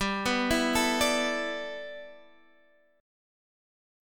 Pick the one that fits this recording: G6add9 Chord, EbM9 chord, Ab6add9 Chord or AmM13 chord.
G6add9 Chord